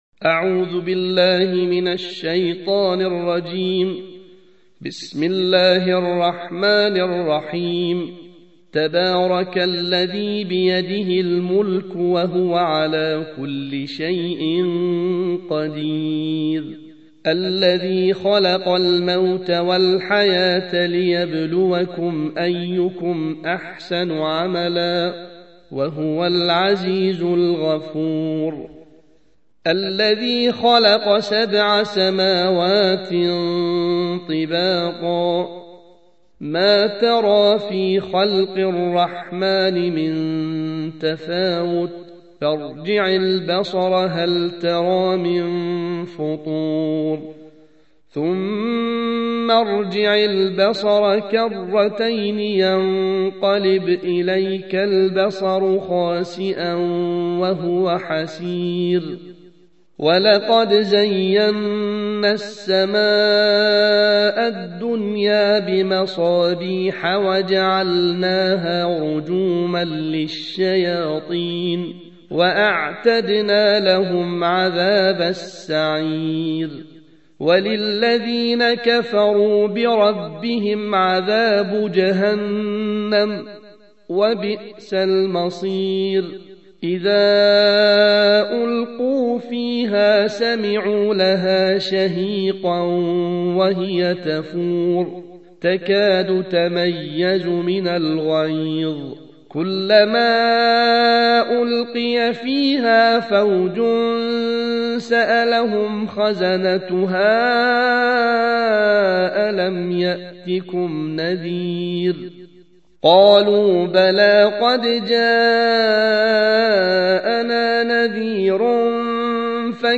الجزء التاسع والعشرون / القارئ